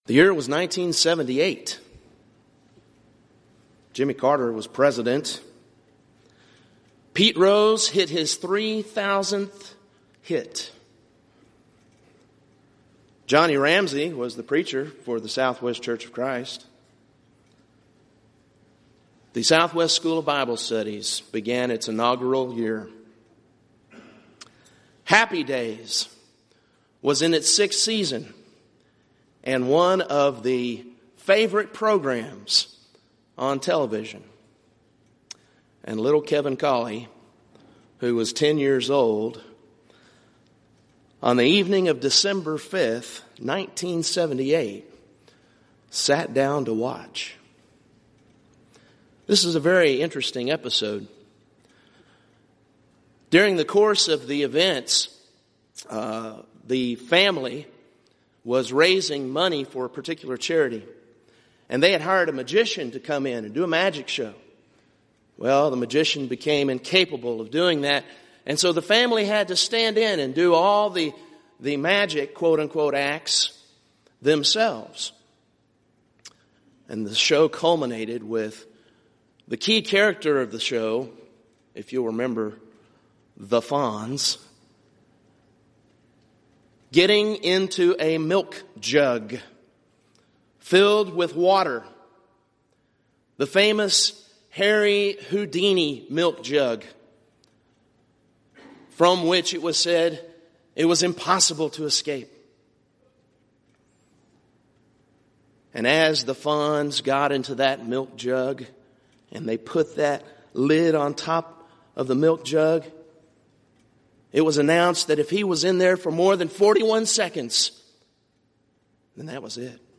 Event: 29th Annual Southwest Lectures Theme/Title: Proclaiming Christ: Called Unto Salvation
lecture